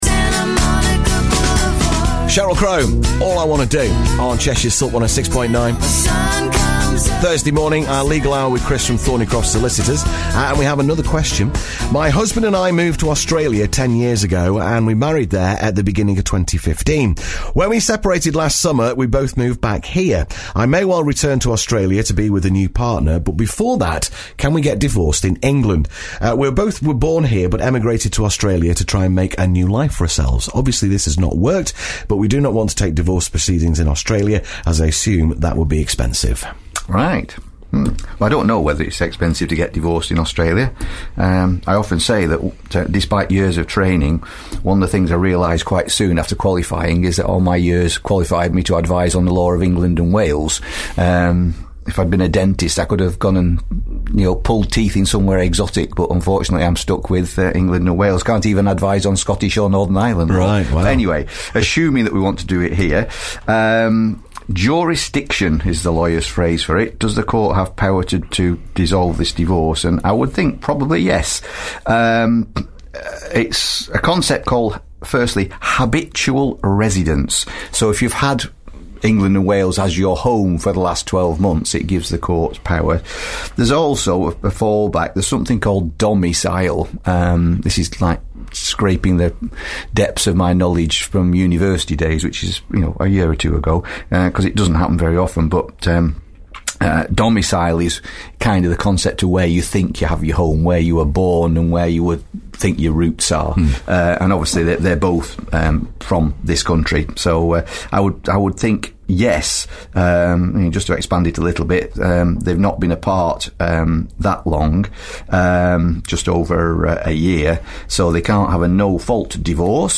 Family Law Matters discussed Live on Silk 106.9